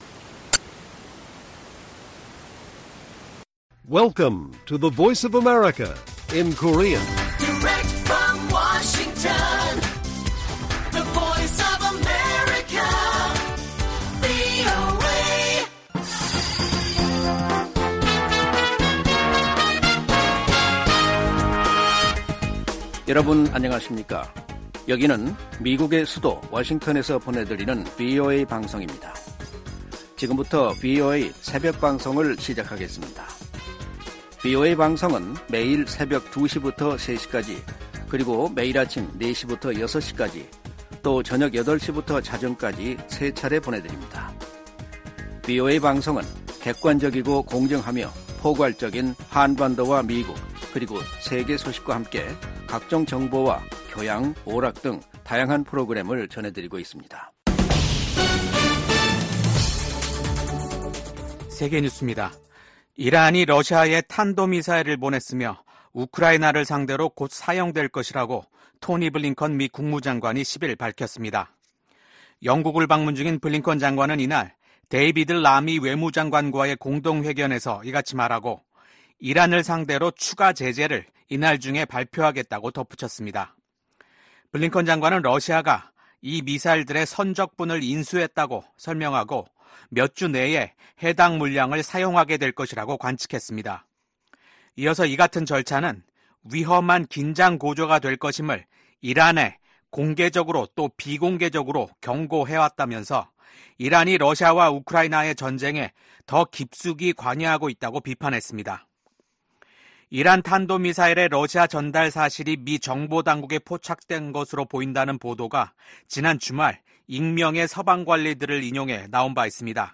VOA 한국어 '출발 뉴스 쇼', 2024년 9월 11일 방송입니다. 민주당 대통령 후보인 카멀라 해리스 부통령이 당선되면 동맹을 강화하며 국제 지도력을 발휘할 것이라는 입장을 재확인했습니다. 북한 사립대학 외국인 교수진의 복귀 소식에 미국 국무부는 미국인의 ‘북한 여행 금지’ 규정을 상기했습니다. 김정은 북한 국무위원장이 9.9절을 맞아 미국의 핵 위협을 주장하며 자신들의 핵 무력을 한계 없이 늘려나가겠다고 밝혔습니다.